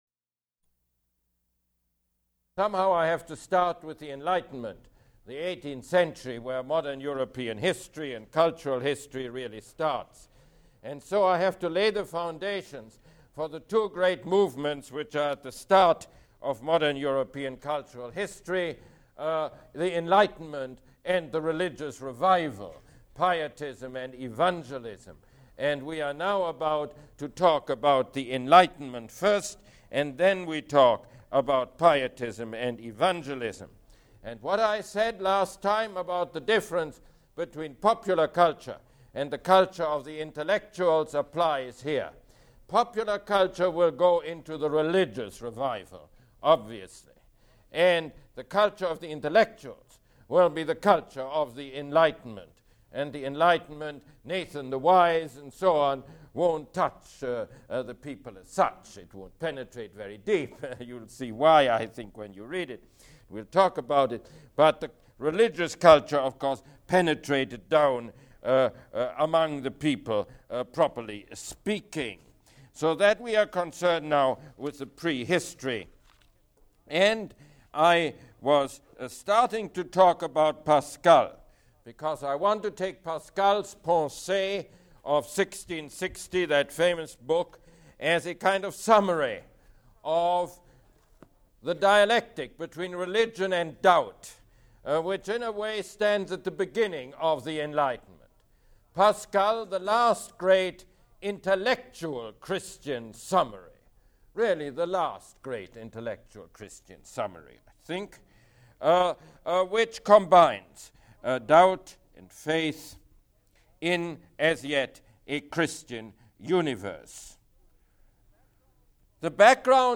Download Lecture03.mp3